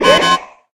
enemy detected.ogg